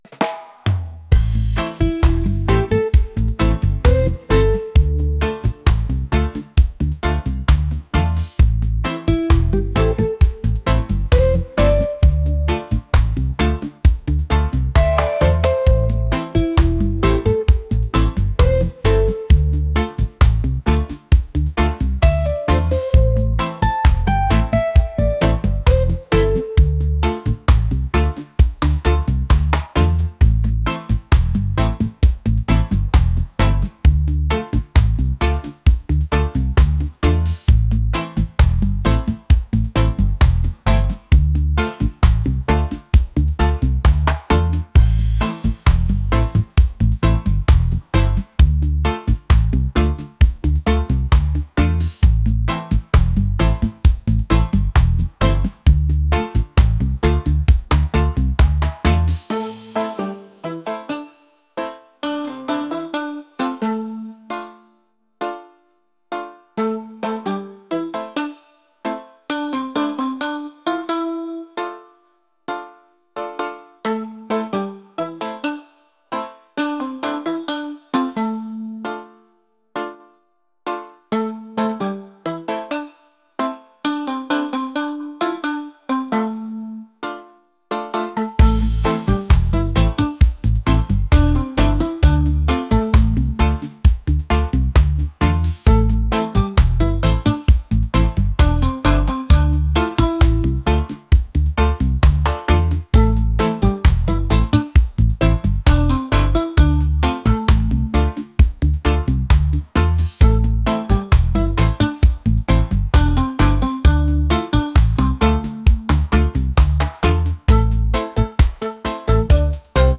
reggae | lofi & chill beats | lounge